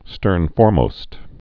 (stûrnfôrmōst)